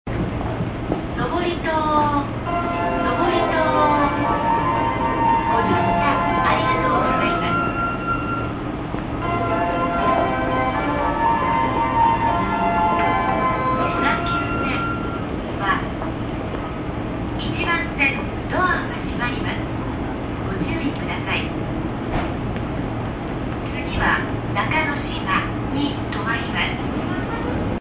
登戸駅メロディ
特殊な言い回しの放送です。
通常の発車放送のあとに、次の停車駅をいう設定に変更された。